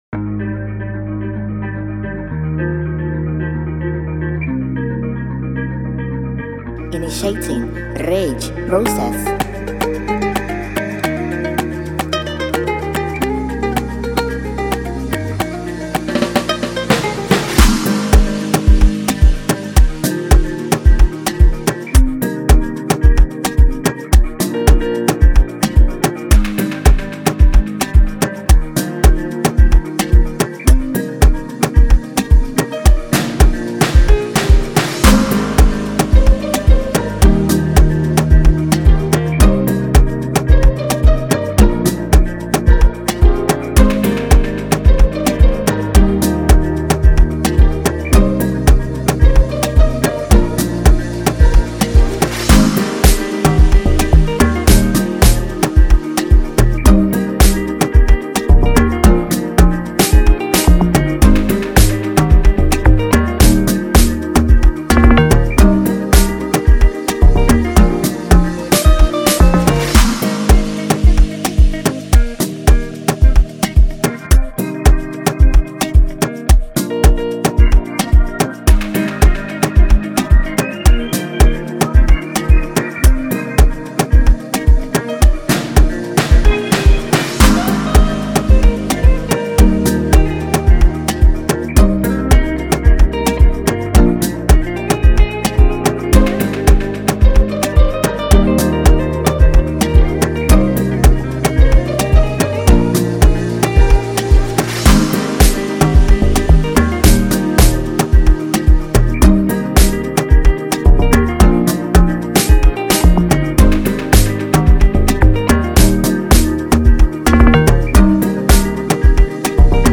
official instrumental
2024 in Dancehall/Afrobeats Instrumentals